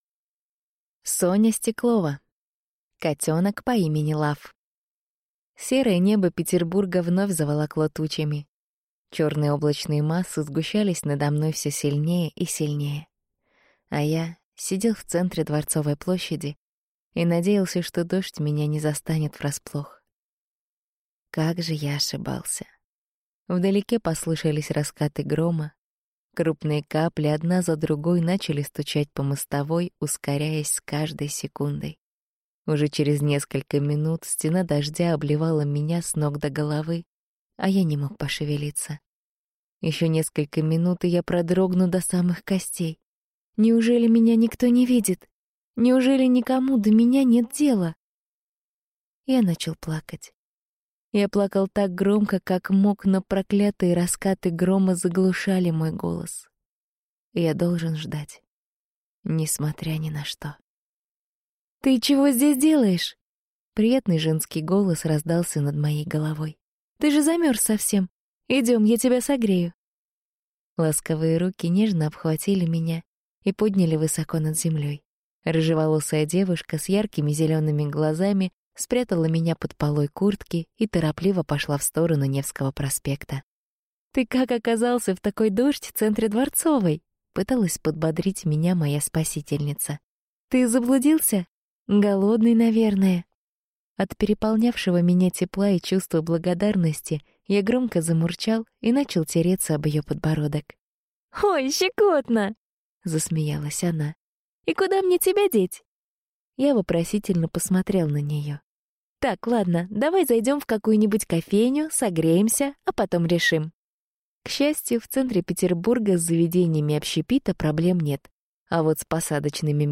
Аудиокнига Котенок по имени Love | Библиотека аудиокниг
Прослушать и бесплатно скачать фрагмент аудиокниги